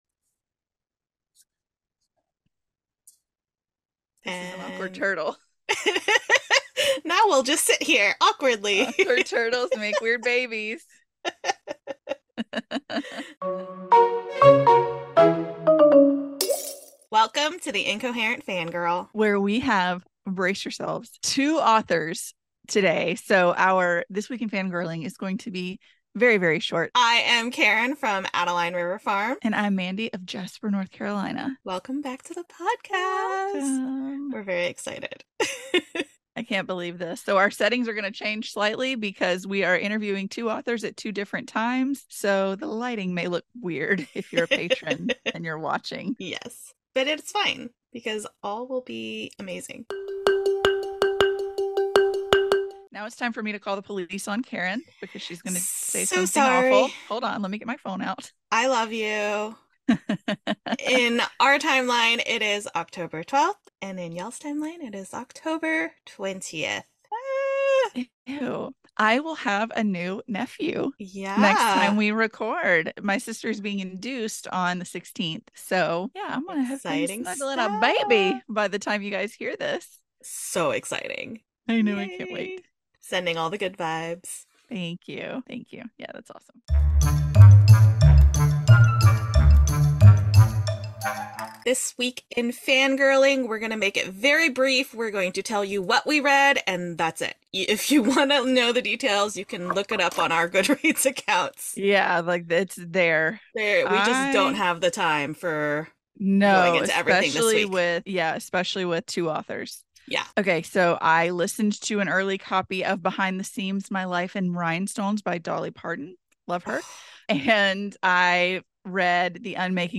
Don’t worry about it, that’s just The Fangirls freaking out about talking to not one, but TWO amazing and talented authors this week!